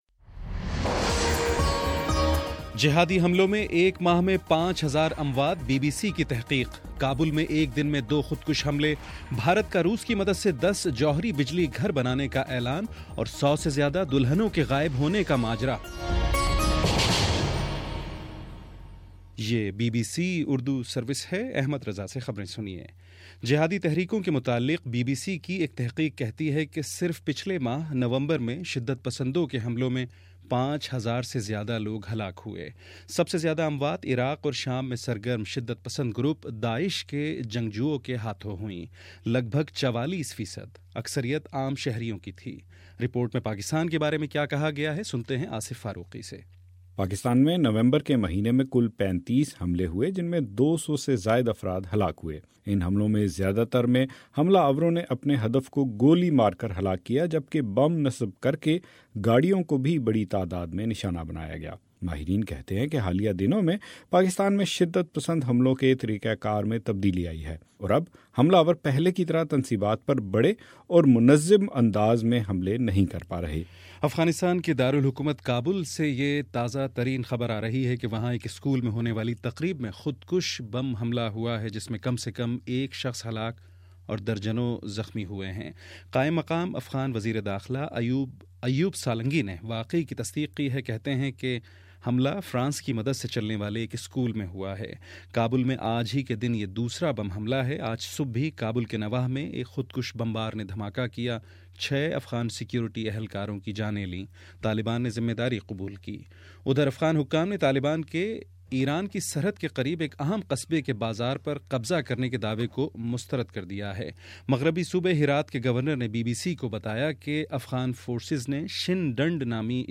دسمبر 11 : شام سات بجے کا نیوز بُلیٹن